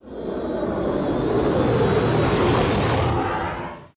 XWing-Fly3